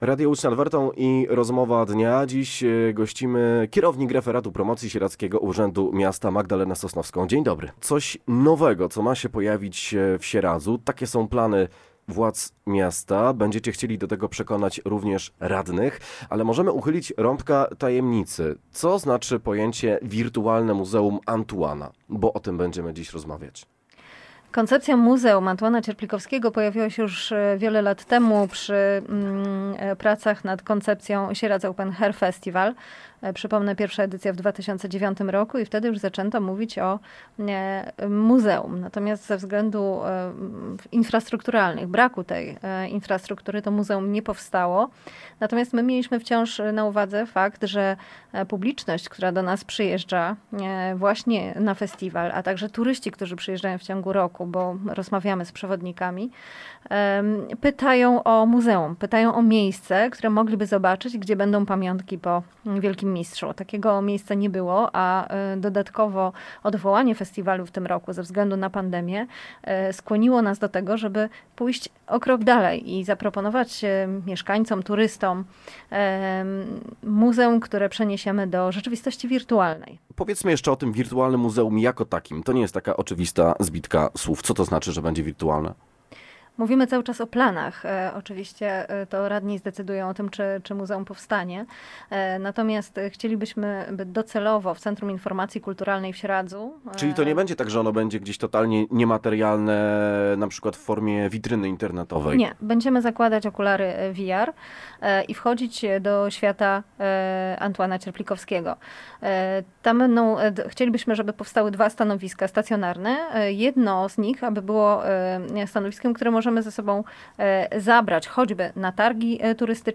Posłuchaj całej rozmowy: Nazwa Plik Autor – brak tytułu – audio (m4a) audio (oga) Warto przeczytać Bistrita 2025.